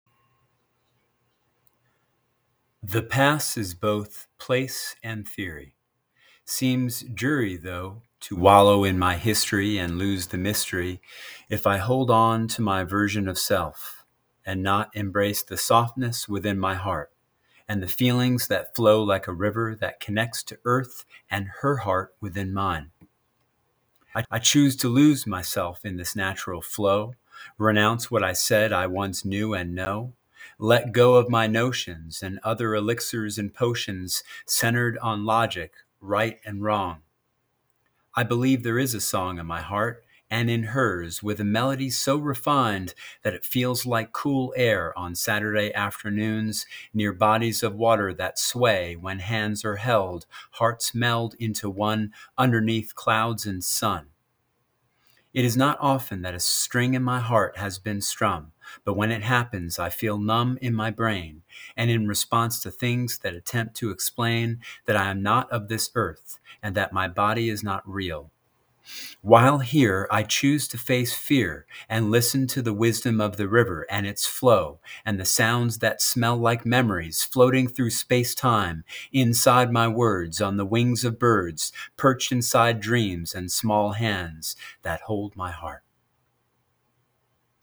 by | Apr 4, 2023 | Spoken Poetry